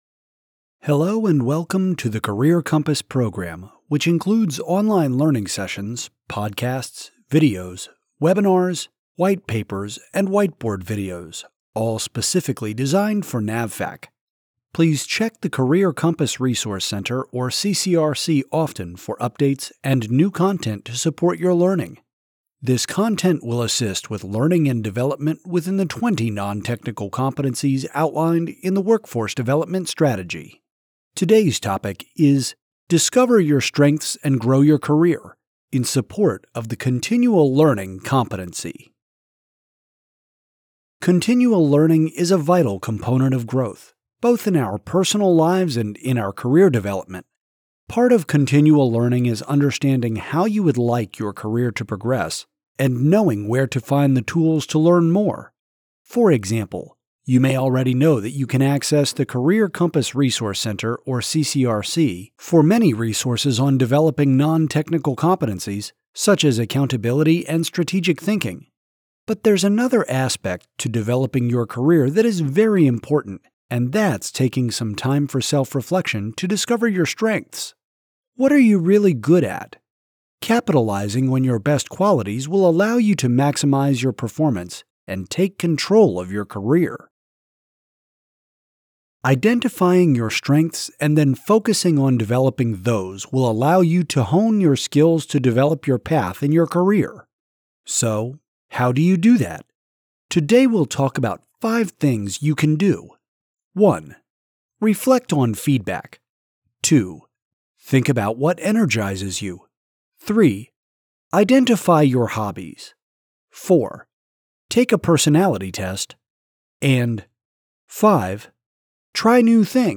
These 5 – 10 minute podcasts include facilitated discussions on select competency-related topics. They contain tips and techniques listeners can learn and quickly apply on-the-job.